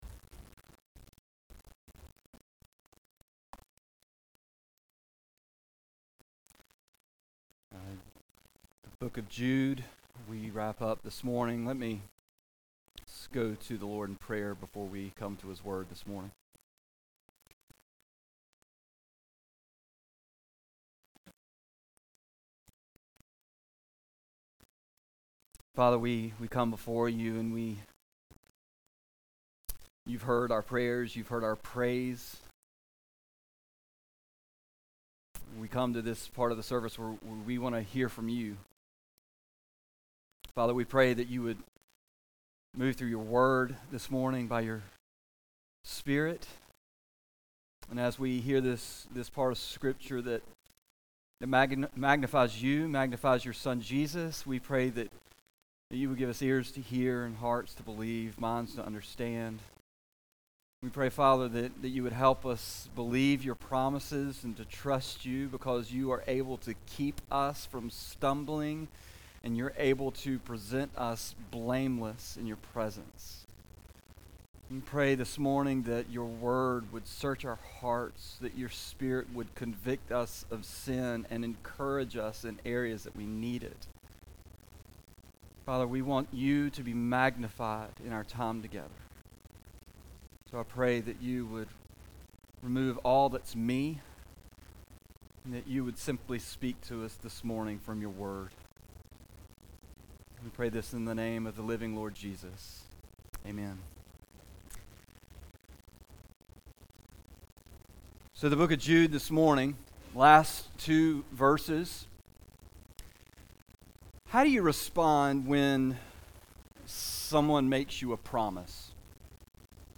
A message from the series "Jude."